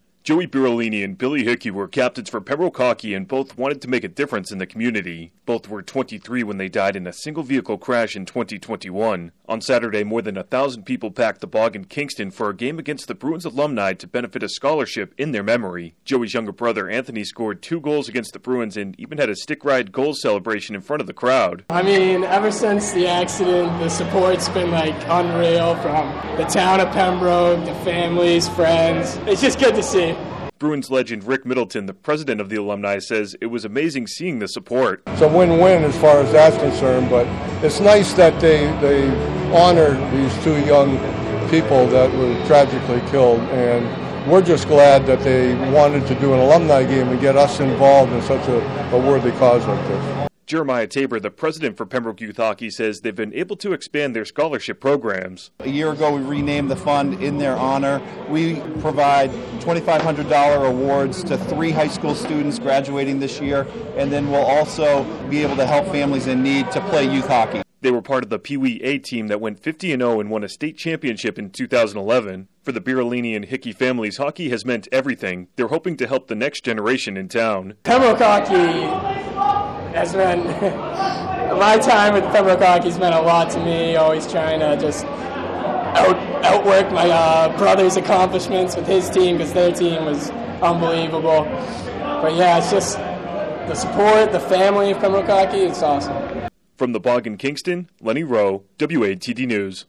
by | Apr 25, 2022 | News